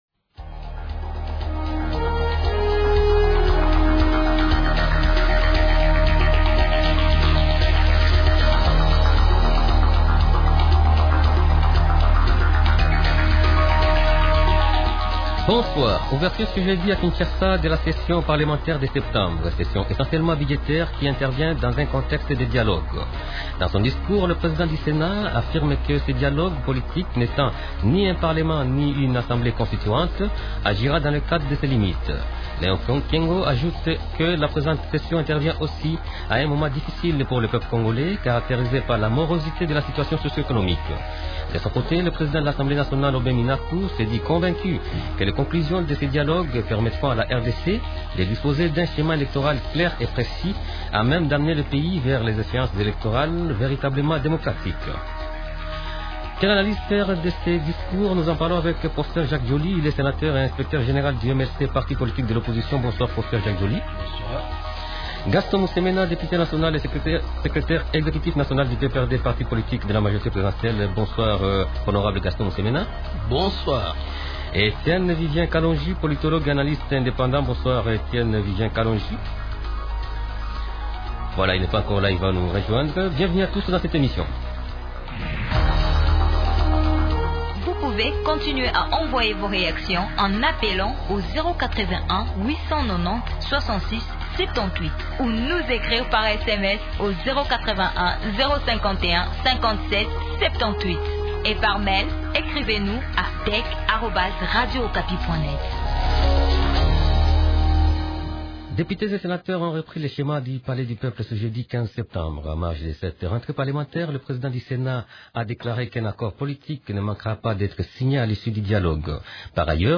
Quelle analyse faire de ces deux discours ?